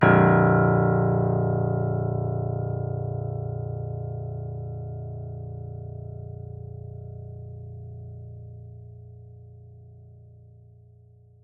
piano-sounds-dev
Vintage_Upright